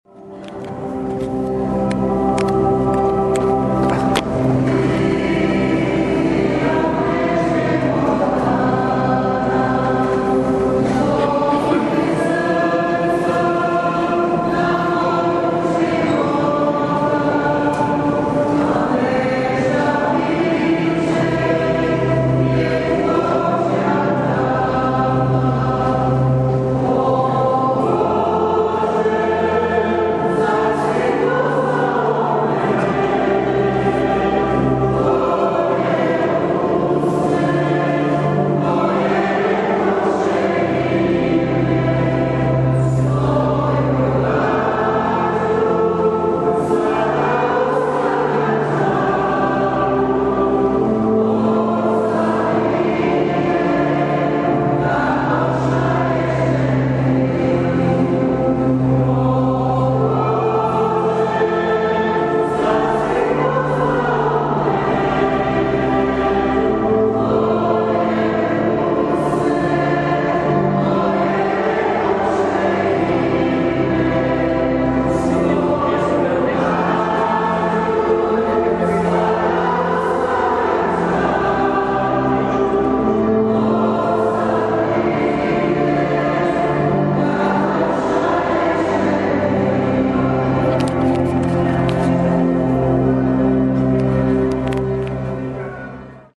ŽUPNI ZBOR – AUDIO:
završna pjesma – ŽUPNI ZBOR